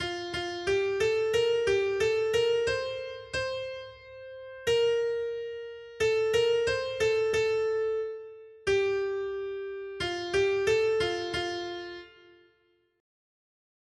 Noty Štítky, zpěvníky ol578.pdf responsoriální žalm Žaltář (Olejník) 578 Skrýt akordy R: Vysvoboď nás, Pane, pro svou lásku! 1.